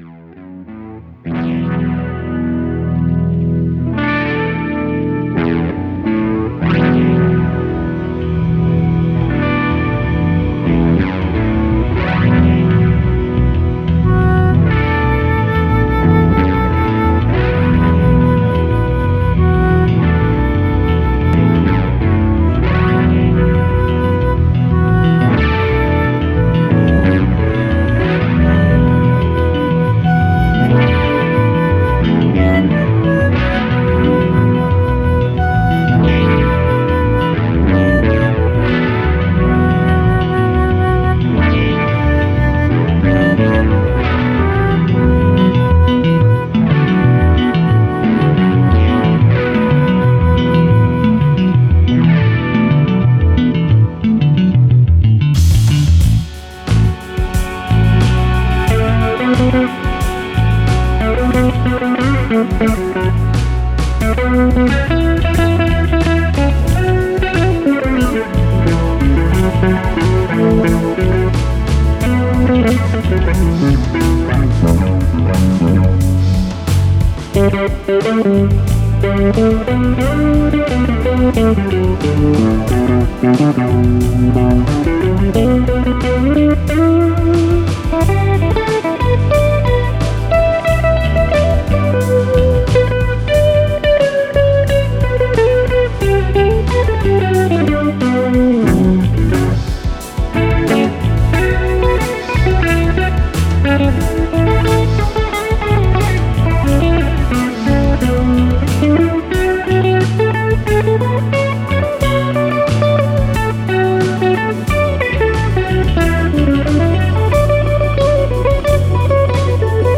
im dunklen, hypnotischen Schwingen der Musik